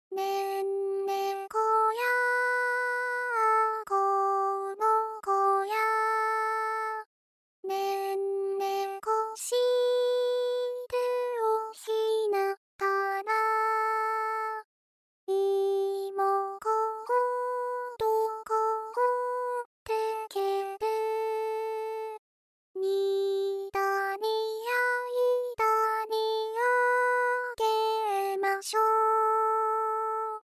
こちらは、「ソ」「ラ」「シ」の三音旋律ですが、二小節ごとの小終止も、最後の終止も、いずれも真ん中の「ラ」で終わっています。
歌声は、いずれも「初音ミク」を使用。）